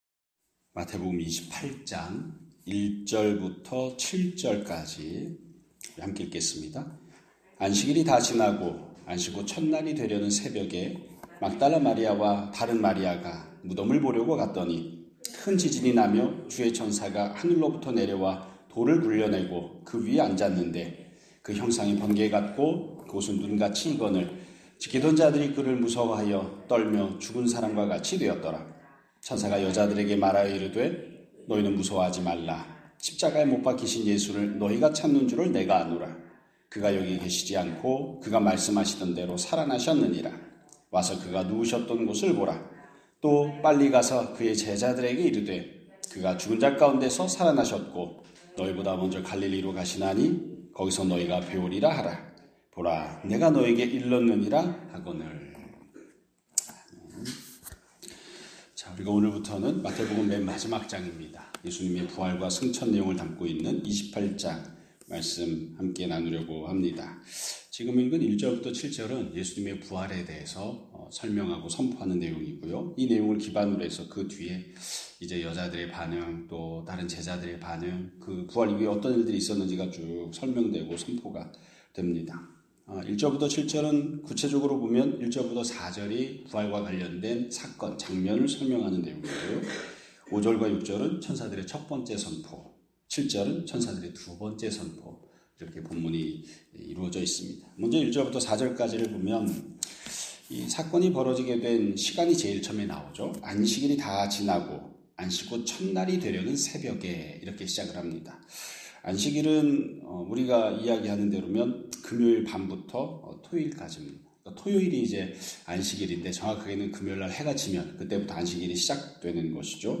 2026년 4월 27일 (월요일) <아침예배> 설교입니다.